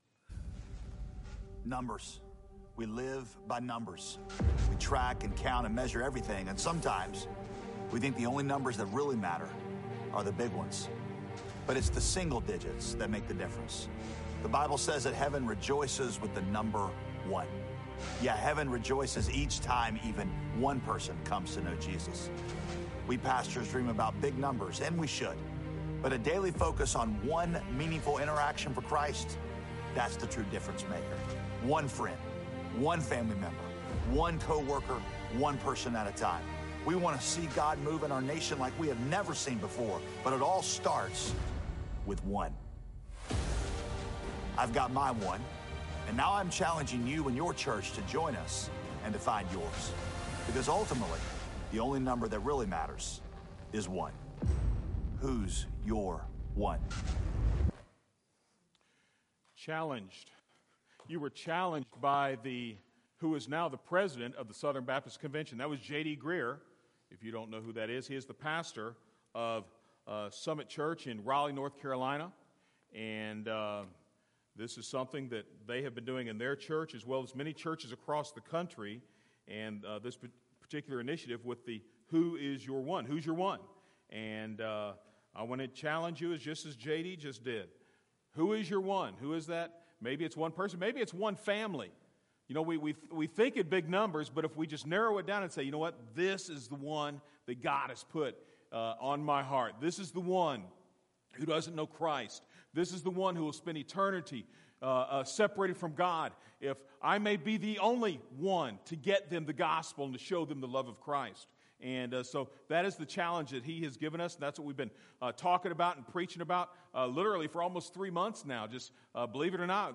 From Series: "Individual Sermons"